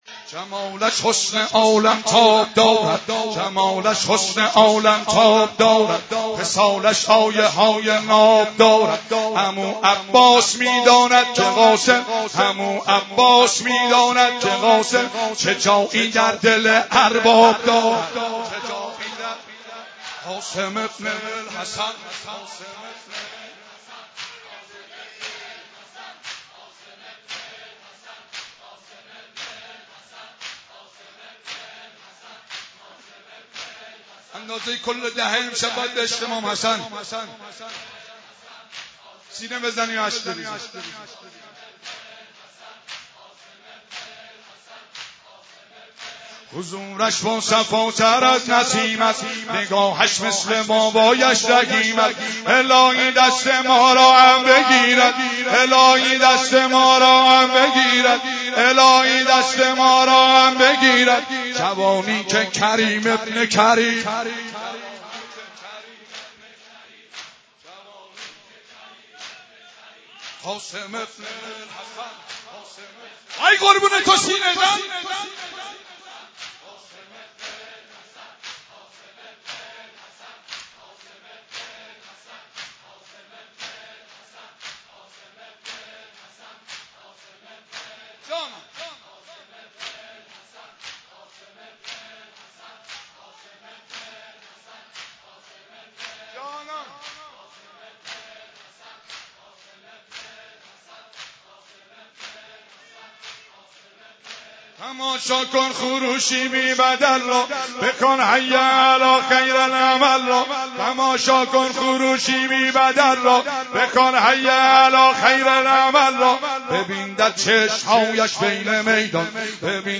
شب ششم